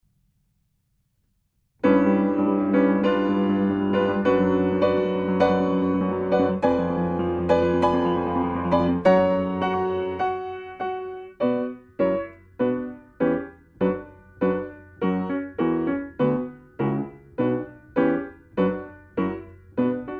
Nagrania dokonane na pianinie Yamaha P2, strój 440Hz
III część – Allegro Moderato: 100 BMP